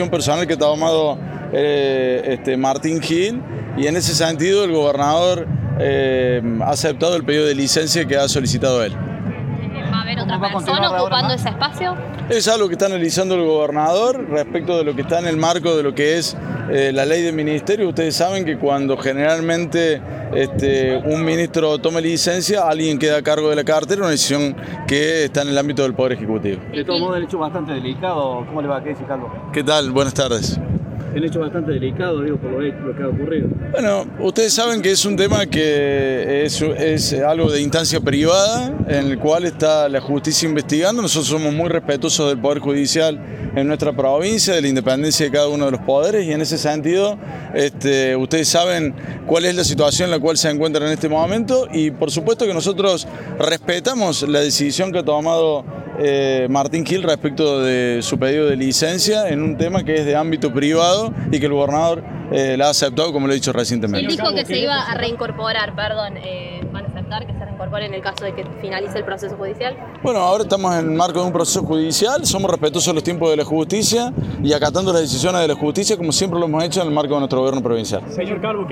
Al finalizar el acto de presentación de la Guardia Urbana, que tuvo lugar ayer en inmediaciones del Parque Pereyra, el ministro de Gobierno Juan Manuel Calvo fue abordado por la prensa para hablar de diversos temas, aunque el más controversial tiene que ver con la licencia que pidió Martín Gill a su cargo como ministro de Cooperativas, a raíz de la investigación judicial en su contra por denuncias de hechos de violencia de género.
CALVO-ENTREVISTA-vale.mp3